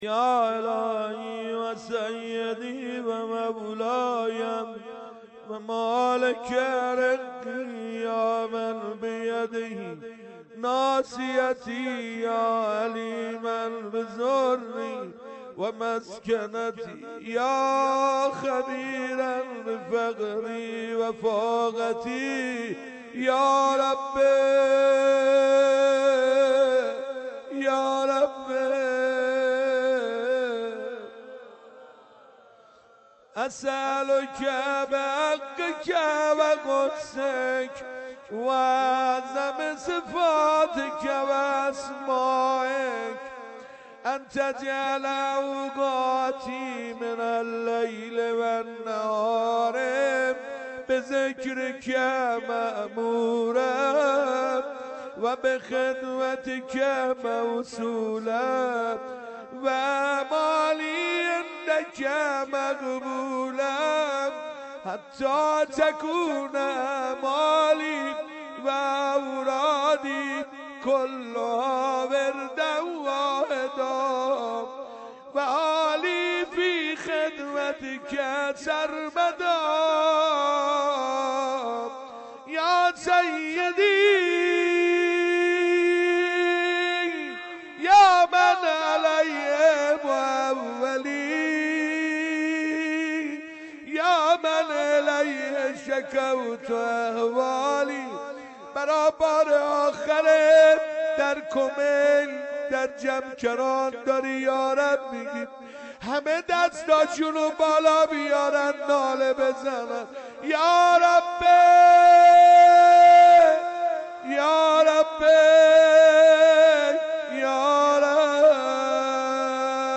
دعای کمیل
شب لیله الرغائب) در مسجد مقدس جمکران